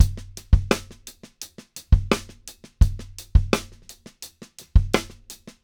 85BPM_Backbeat.wav